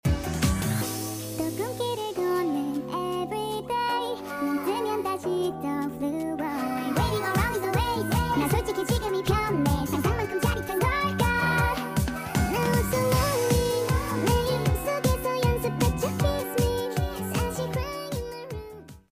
Sped up ver.